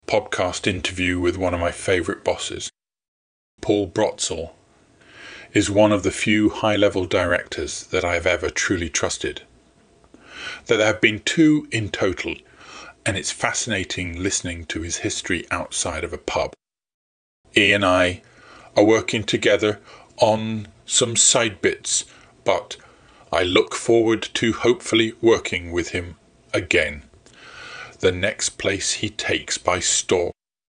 Podcast interview with one of my favourite bosses.